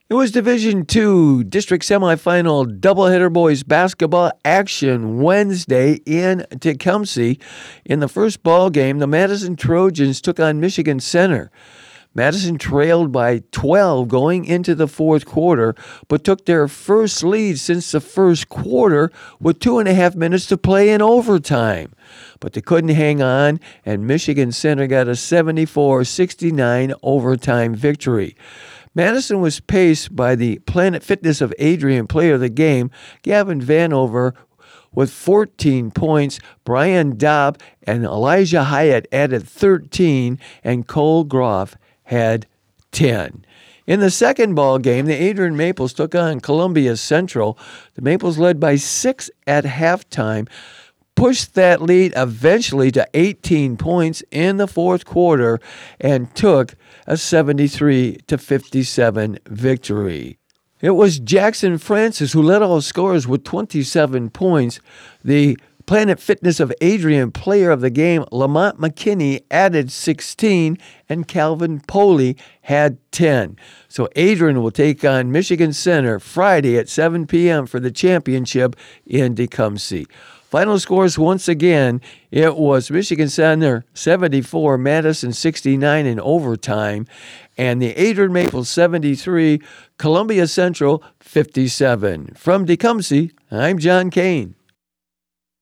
nws8521-aaa_sports_wrap.wav